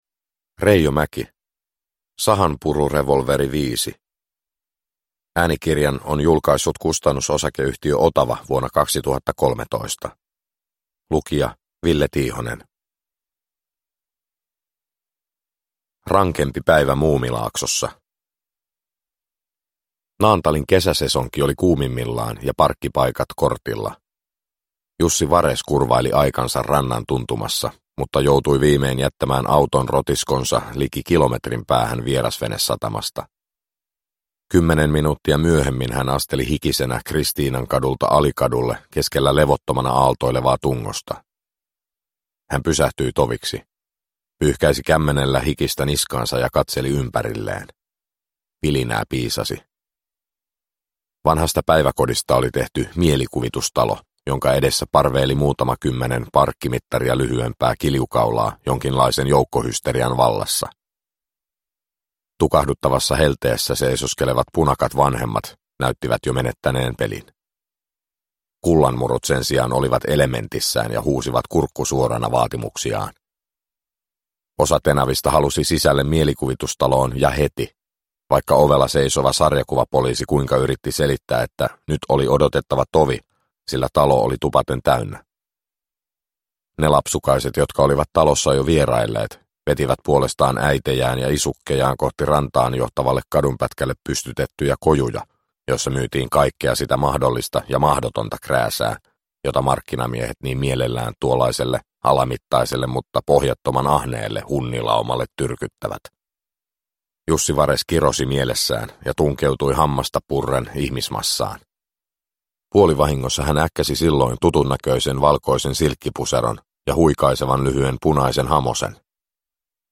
Sahanpururevolveri 5 – Ljudbok – Laddas ner
Uppläsare: Ville Tiihonen